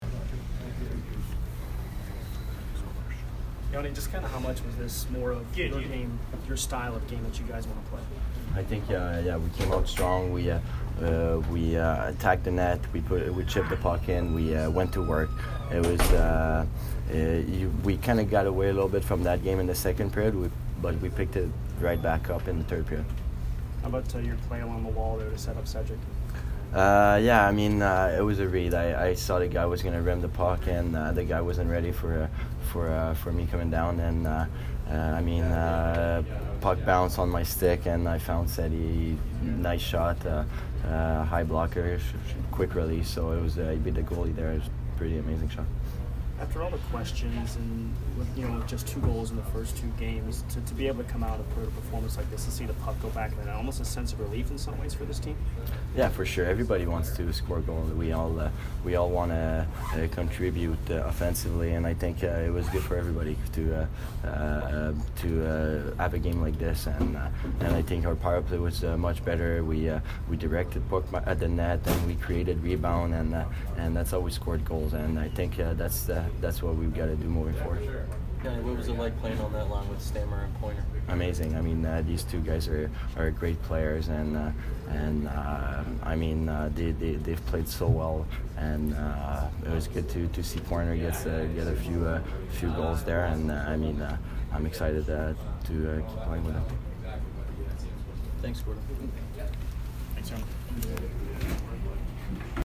Yanni Gourde post-game 10/13